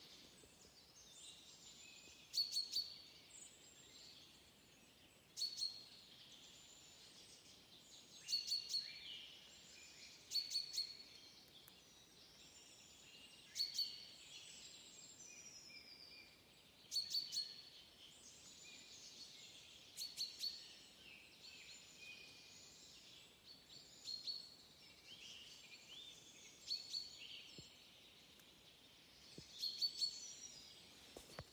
Žubīte, Fringilla coelebs
PiezīmesVai žubītes sauciens?